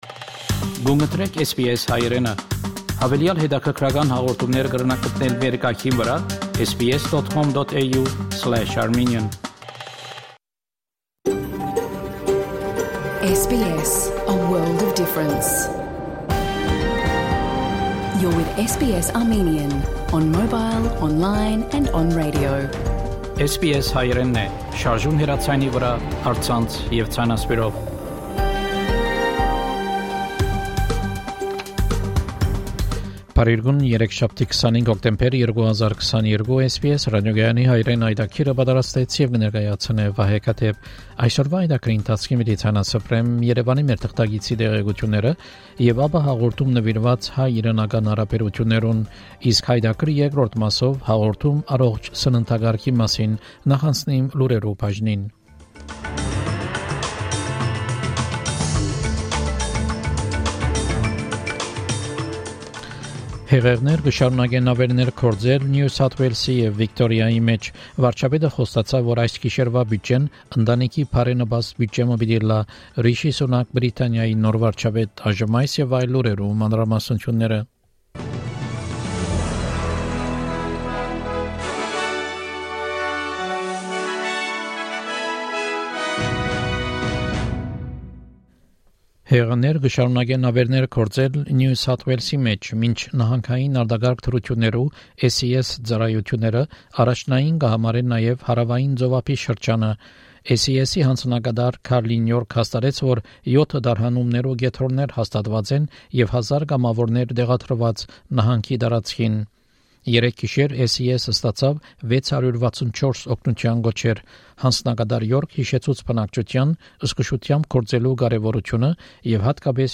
SBS Armenian news bulletin – 25 October 2022
SBS Armenian news bulletin from 25 October 2022 program.